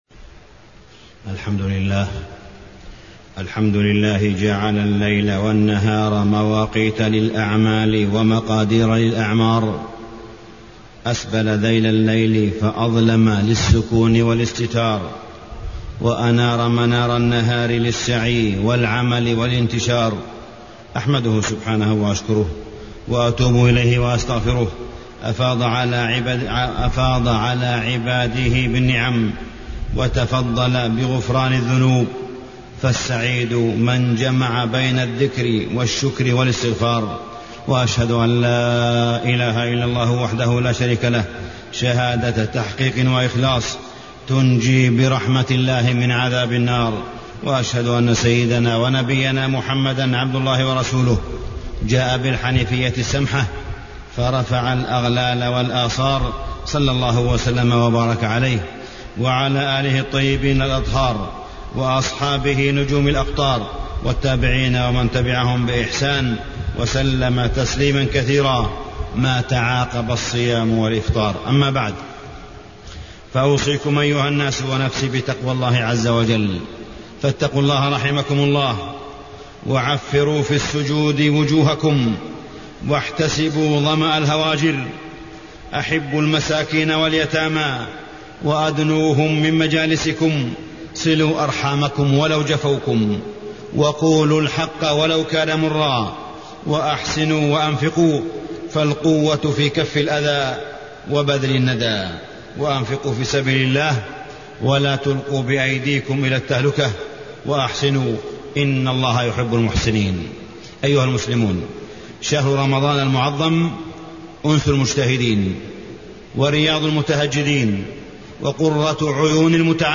تاريخ النشر ١٩ رمضان ١٤٢٩ هـ المكان: المسجد الحرام الشيخ: معالي الشيخ أ.د. صالح بن عبدالله بن حميد معالي الشيخ أ.د. صالح بن عبدالله بن حميد التقرب إلى الله